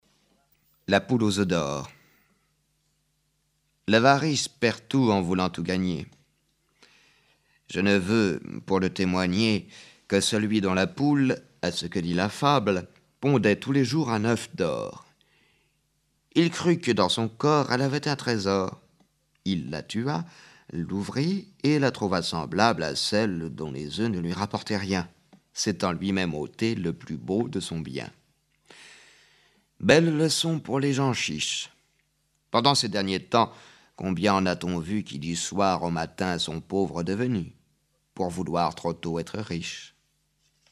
フランス人による朗読音声